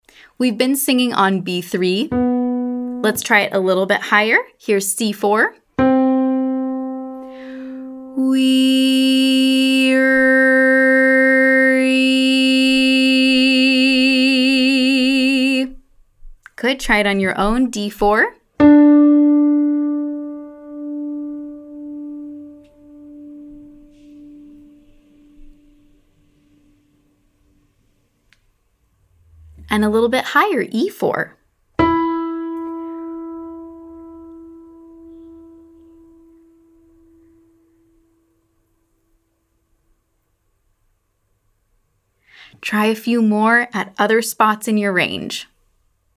Listen carefully and you’ll notice the second EE vowel you sing has much more “ring” to it than the first.
Exercise 2: WEE-RR-EE (single pitch)
Pop Course Day 4 Exercise 2